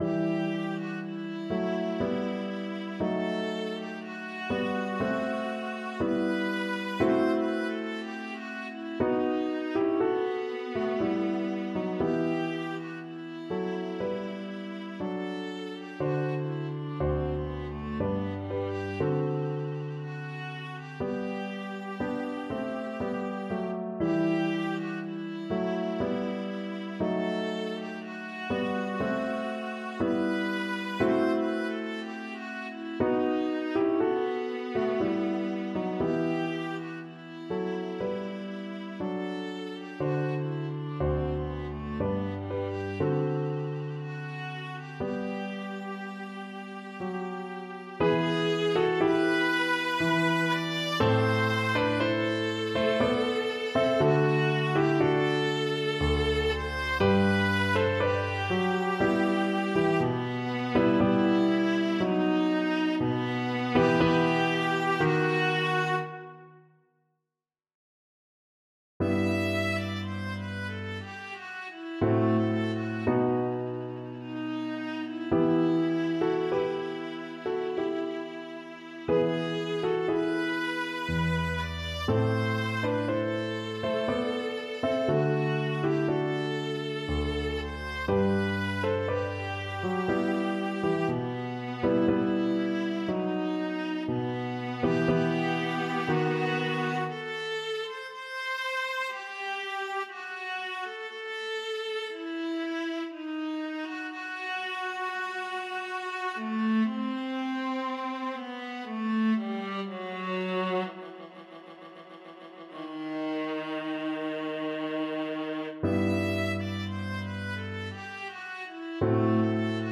3/4 (View more 3/4 Music)
Largo con espressione =60
Classical (View more Classical Viola Music)